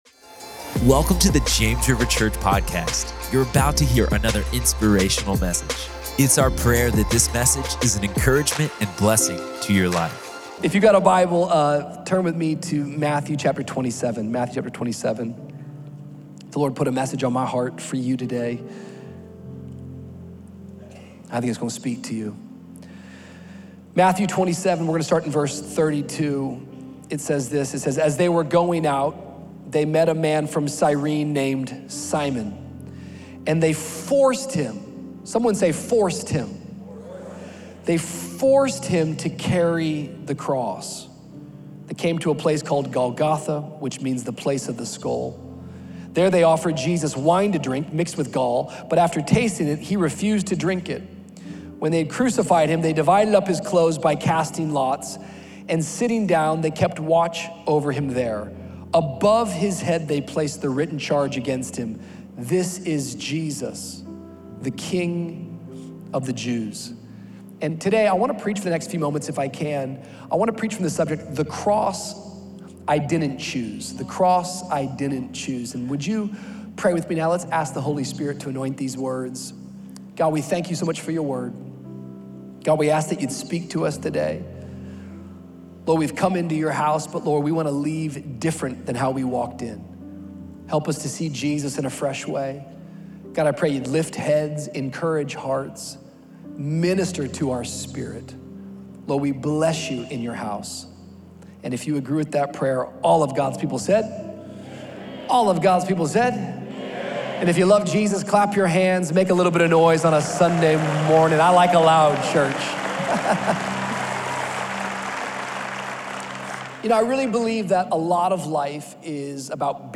In this powerful message from Matthew 27:32–37, Pastor Rich Wilkerson Jr. reminds us that sometimes the most life-changing moments are the ones we didn’t plan.